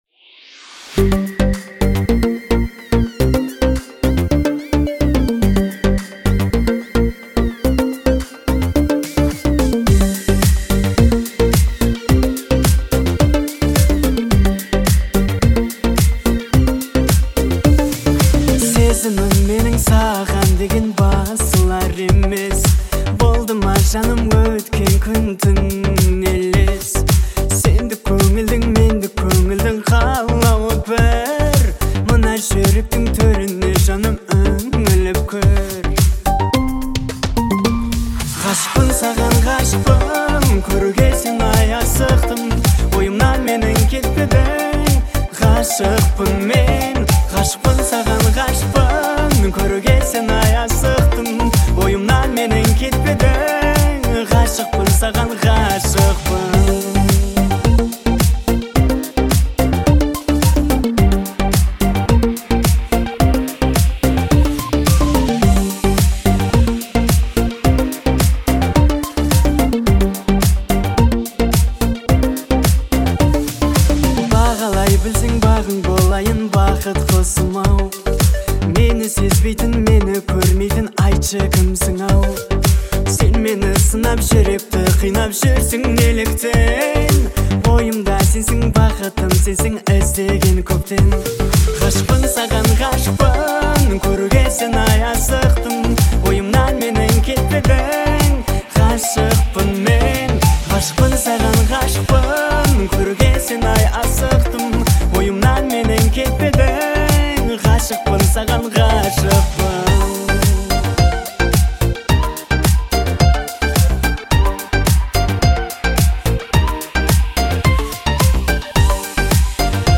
это яркий пример казахской поп-музыки